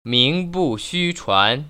[míng bù xū chuán] 밍부쉬추안  ▶